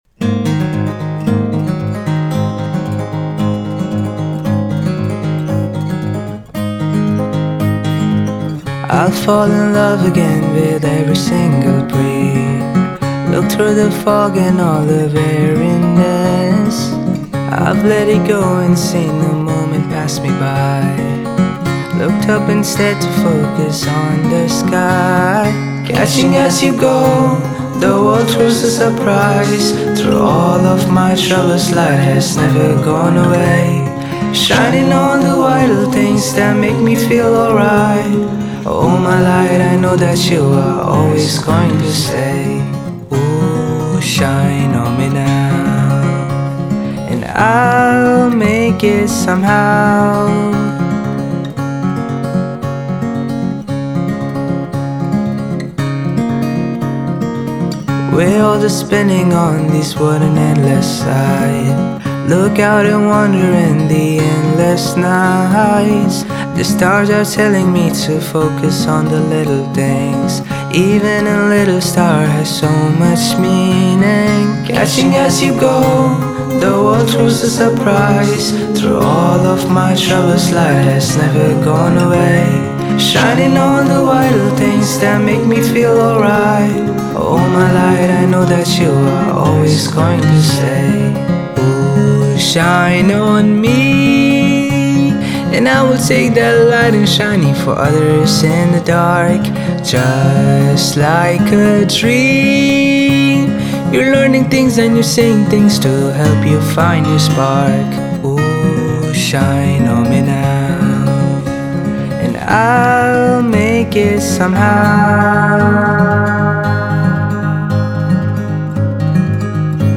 Indie Folk
In dem Fall habe ich mir gedacht: Schlichter Gitarren-Lagerfeuersong. Reiner Gitarrensound mit charaktervoller Stimme und vollen Fokus auf Melodie/Stimme.
Darunter Stereobreite stark verringert damit es kuscheliger wird, ordentlich Distortion reduziert, BGs hochgezogen, den Vocals und GItarren mehr Speck gegeben und die Vox vorn ans Fenster geklebt.